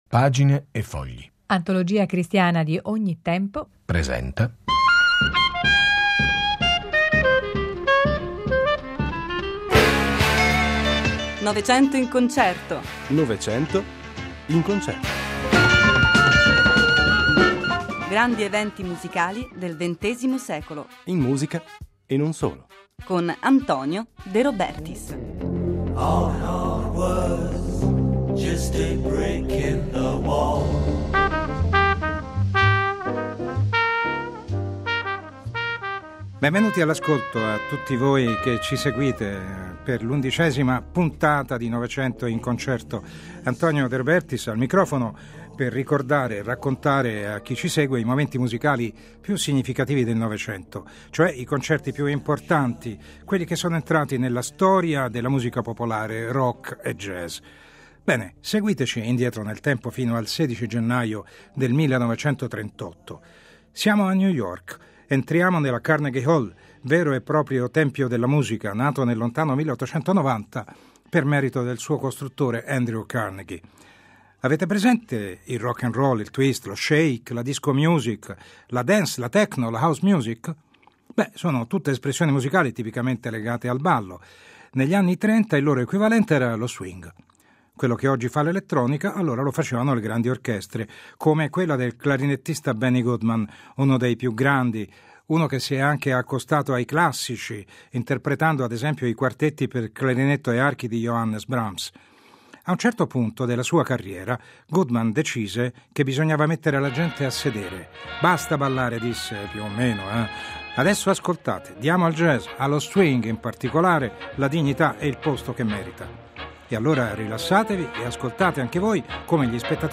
Mentre aleggia nella Carnegie Hall a New York il suo più grande concerto live , nel mondo maturano venti di guerra e l'insidia inumana dell'antiseminitismo. La chiesa mette in guardia l'umanità dei tempi bui che si preannunciano, attraverso il radiomessaggio di Pio XI, parole che cadranno purtroppo nel vuoto.